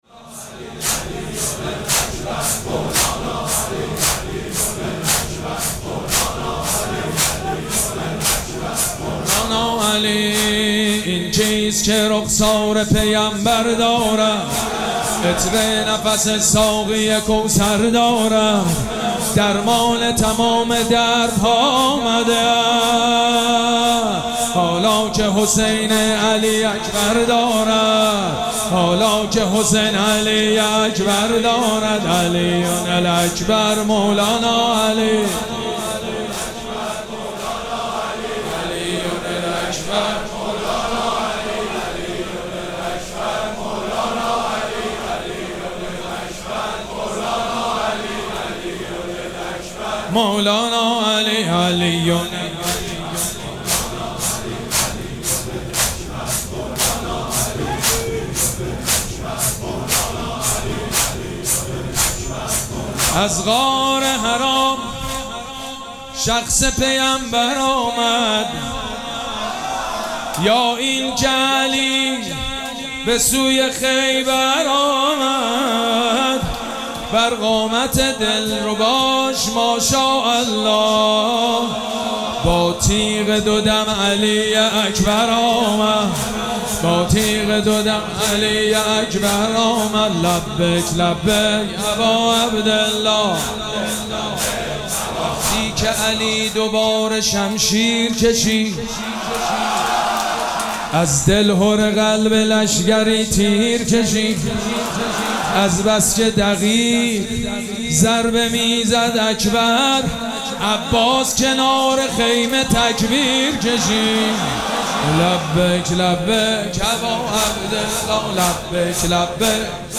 مراسم عزاداری شب هشتم محرم الحرام ۱۴۴۷
حاج سید مجید بنی فاطمه